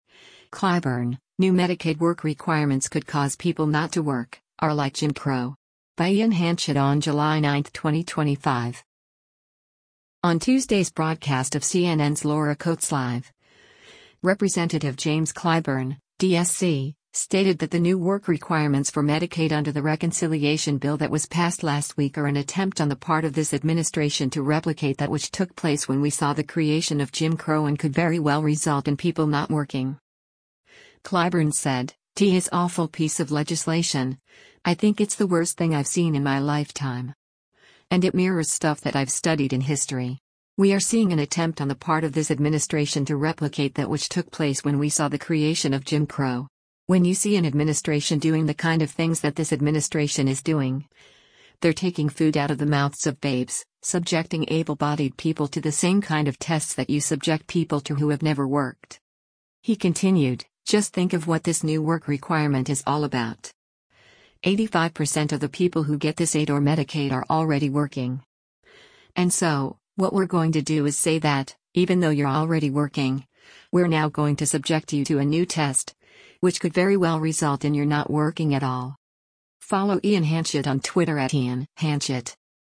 On Tuesday’s broadcast of CNN’s “Laura Coates Live,” Rep. James Clyburn (D-SC) stated that the new work requirements for Medicaid under the reconciliation bill that was passed last week are “an attempt on the part of this administration to replicate that which took place when we saw the creation of Jim Crow” and “could very well result” in people not working.